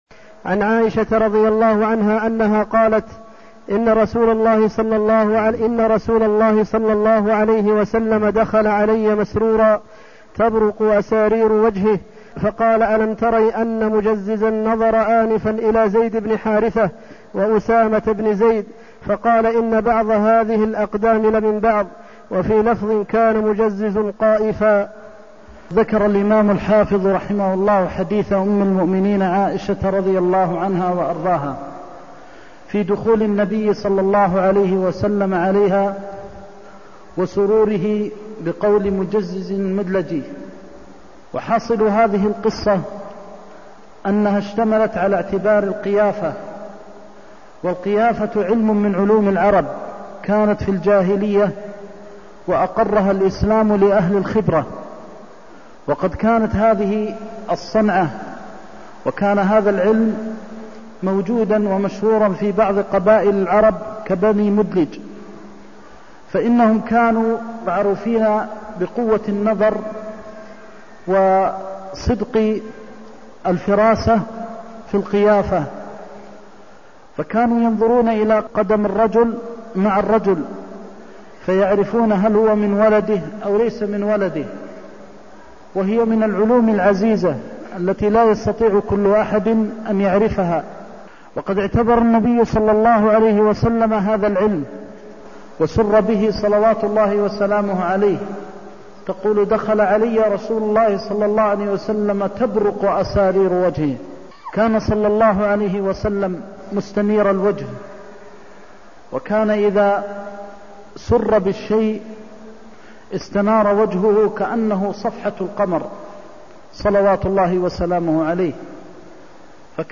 المكان: المسجد النبوي الشيخ: فضيلة الشيخ د. محمد بن محمد المختار فضيلة الشيخ د. محمد بن محمد المختار إن بعض هذه الأقدام لمن بعض (310)